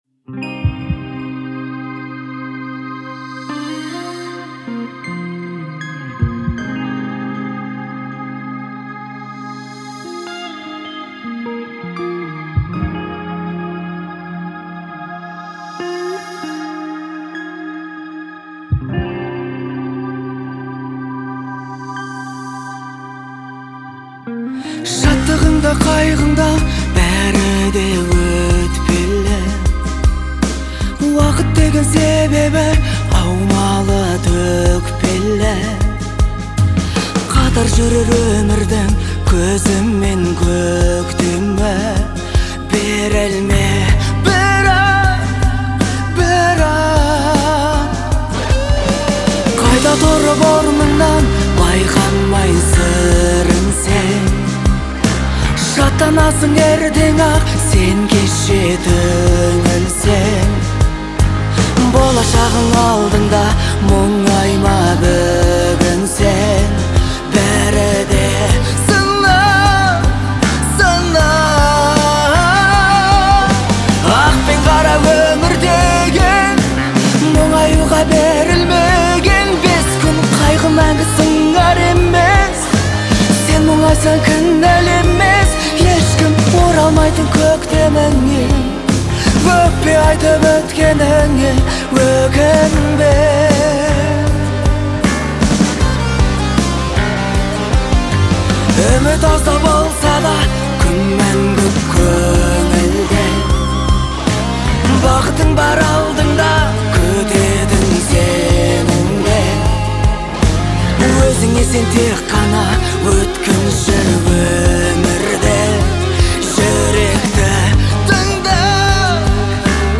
мелодичностью и гармоничными аранжировками